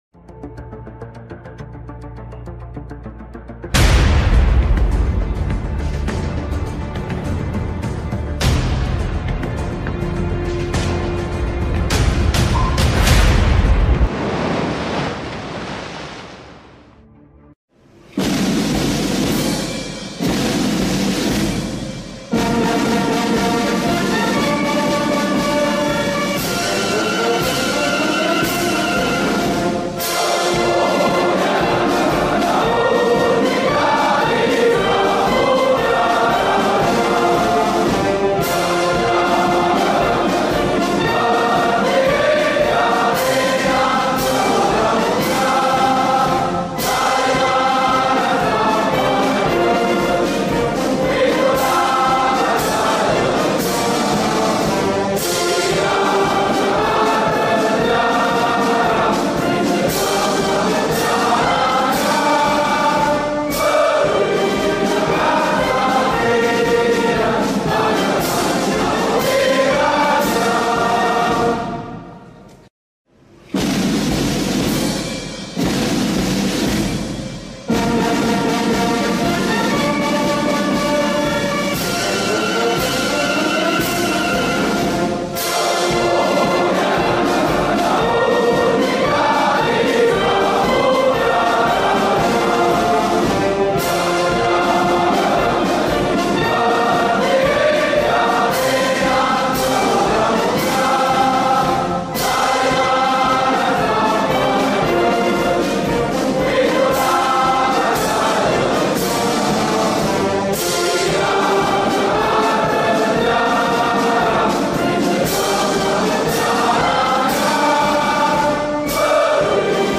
Patriotic Songs
Skor Angklung